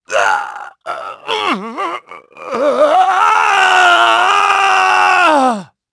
Clause-Vox-Story-Pain_1.wav